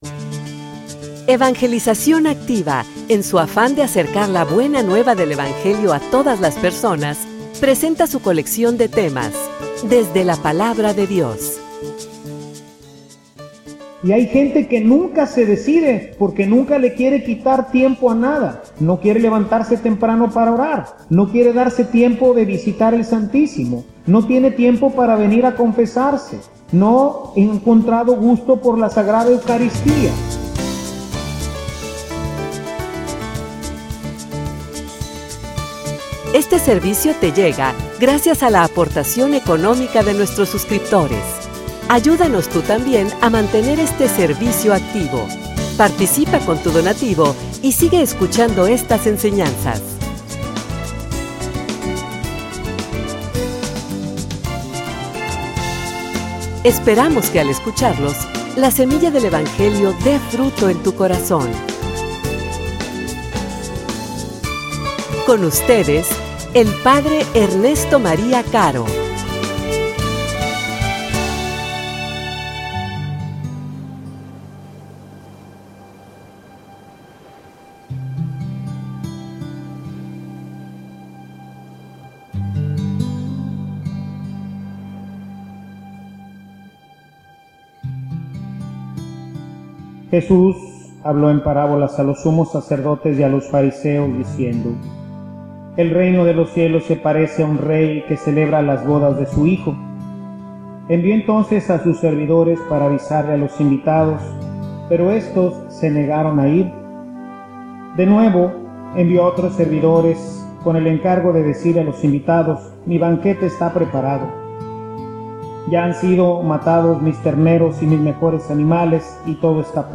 homilia_Acepta_su_invitacion.mp3